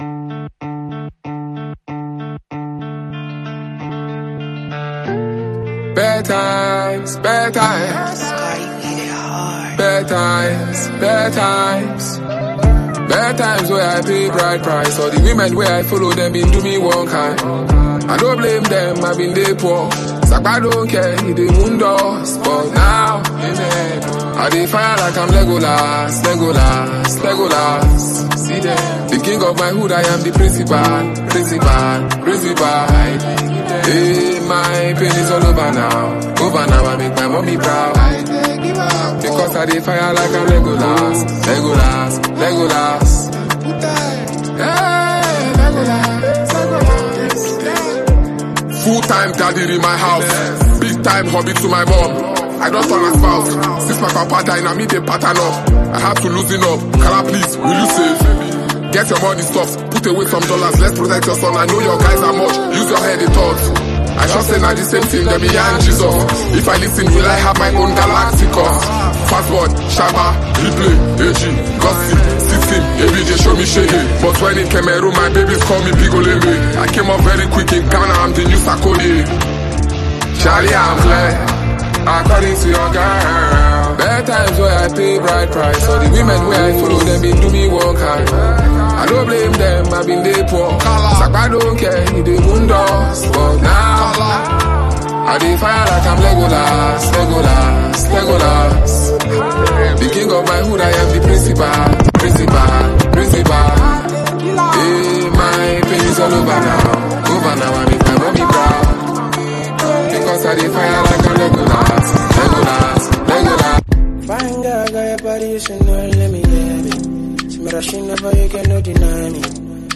this new Dj mix consist of trending hit songs in 2025.